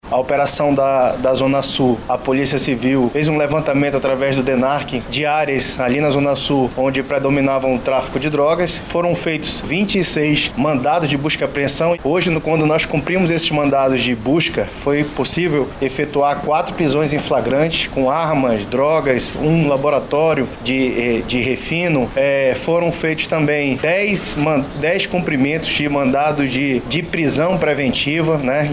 O balanço é do delegado-geral adjunto da Polícia, Guilherme Torres.